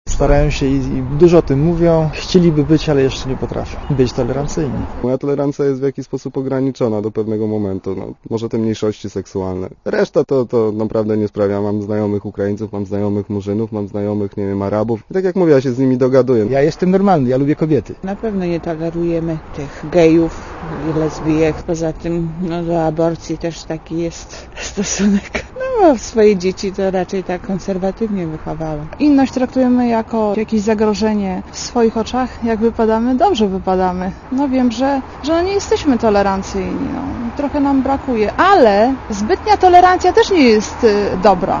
Zapytaliśmy warszawiaków czy są tolerancyjni i czego najbardziej nie tolerują